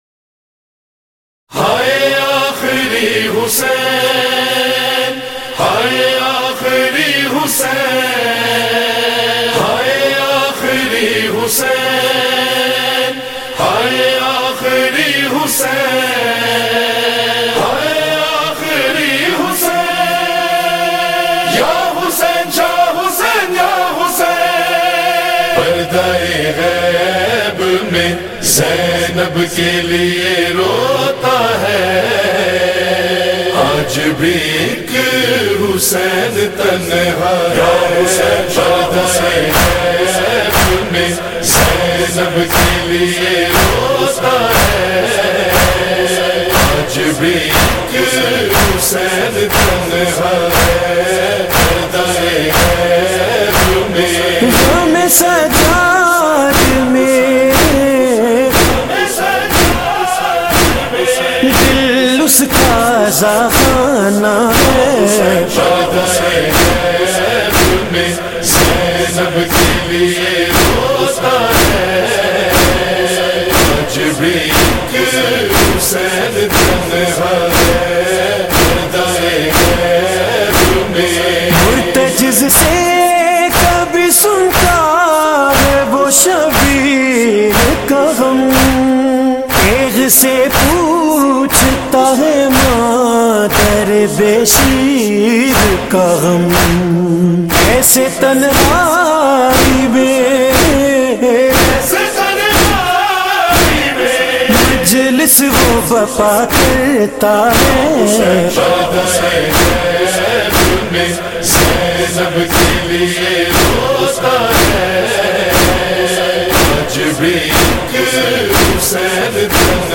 Right Click “Save Link As” to Download Nohay